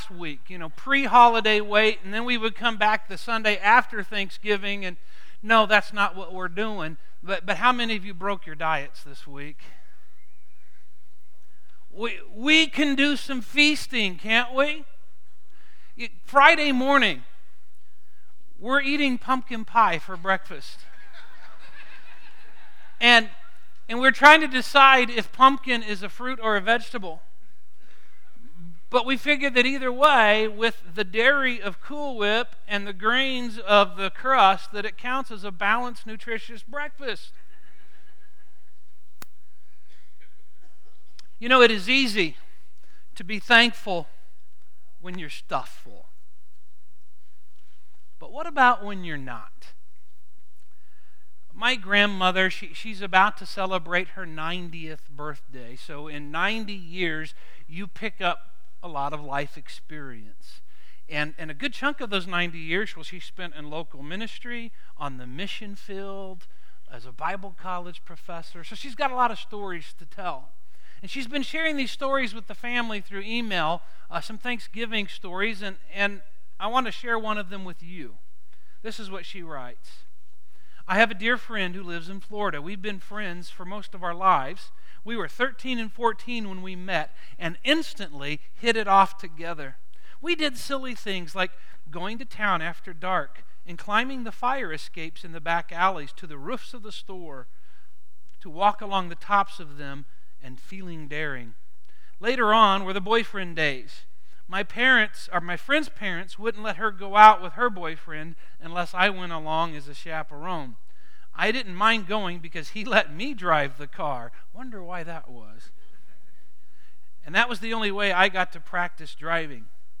However, Scripture invites us to something deeper. In this Thanksgiving message we look at some of the rewards or blessings of having a grateful heart.